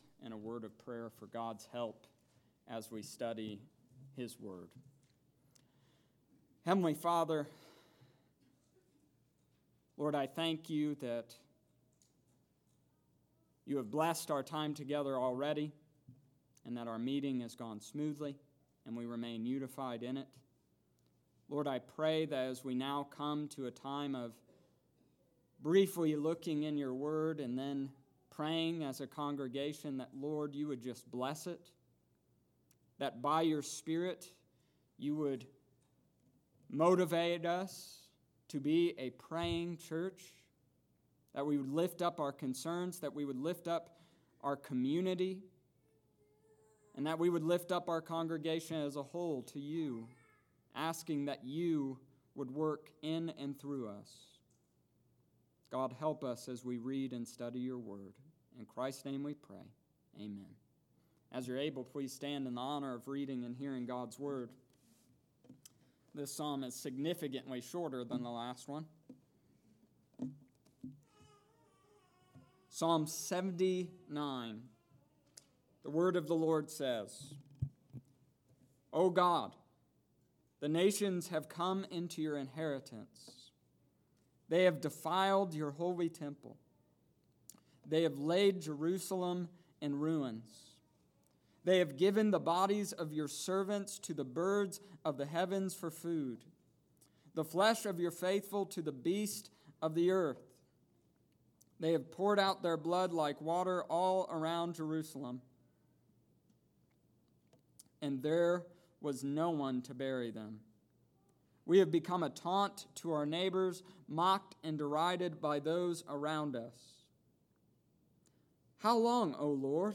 Psalm chapter 79 verses 1-13 August 14th, 2019 Wed. night service